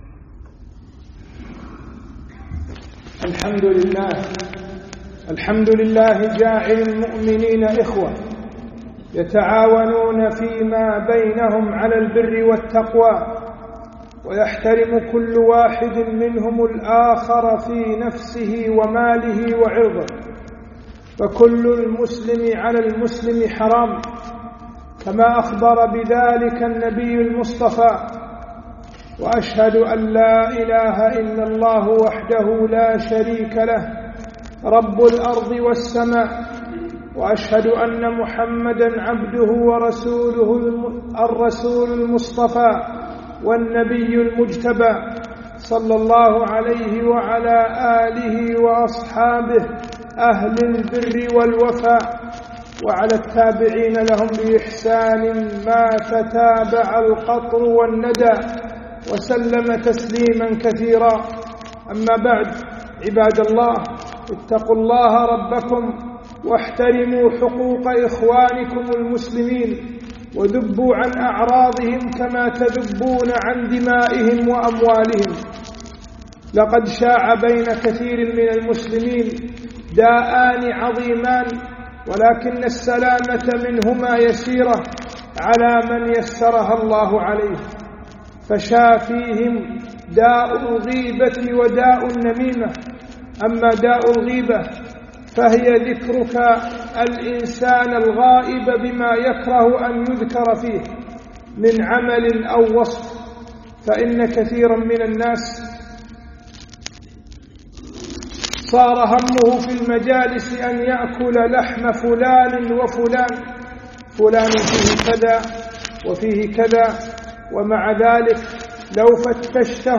خطبة - داء الغيبة والنميمة